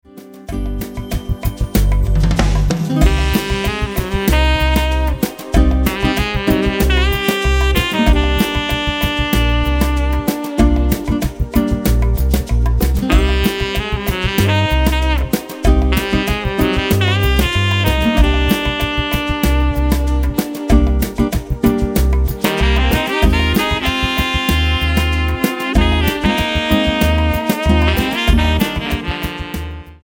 95 BPM